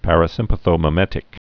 (părə-sĭmpə-thō-mĭ-mĕtĭk, -mī-)